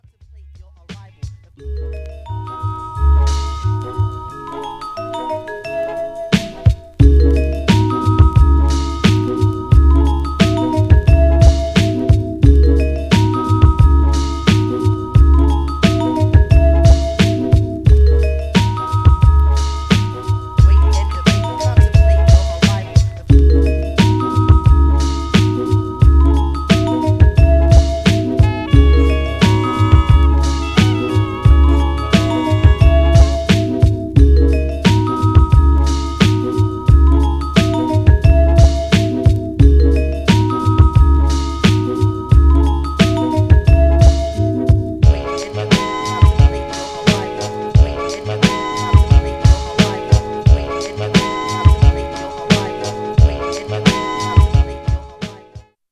Styl: Hip Hop, Lounge